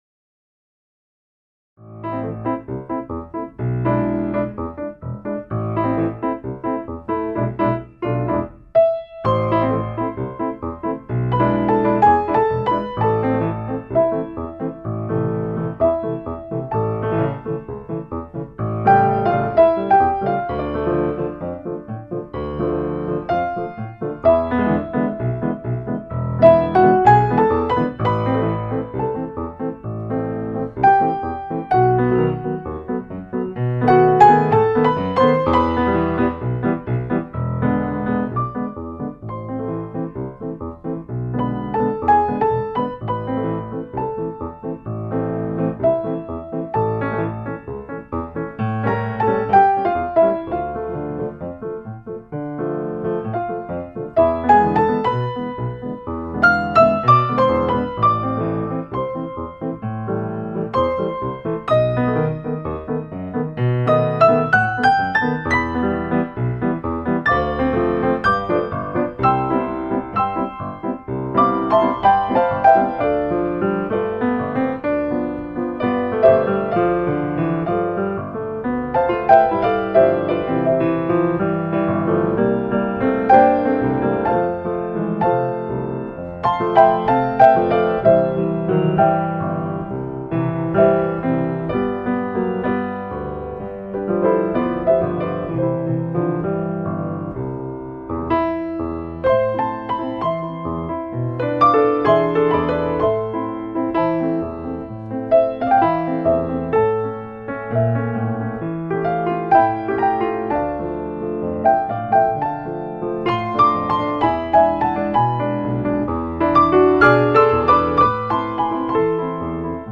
动感活泼浪漫多情 http